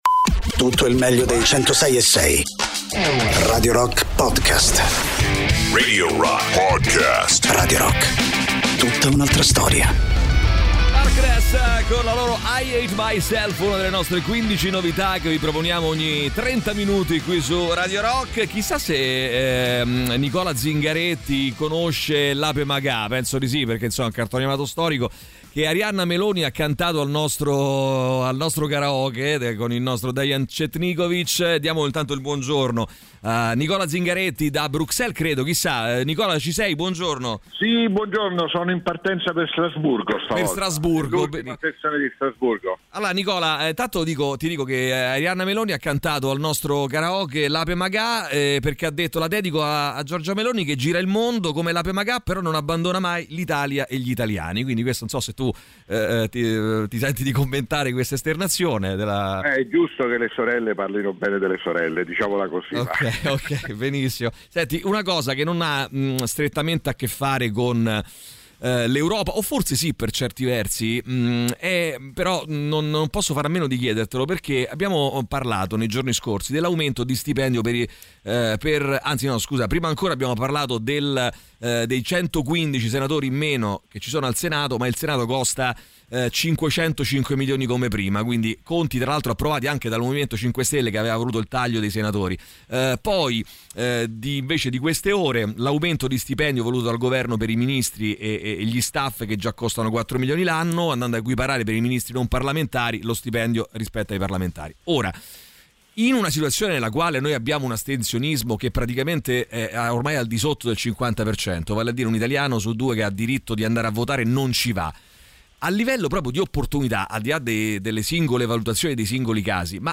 intervistano telefonicamente Nicola Zingaretti, Eurodeputato e capodelegazione del Partito Democratico al Parlamento europeo, durante il ‘The Rock Show’, sui 106.6 di Radio Rock.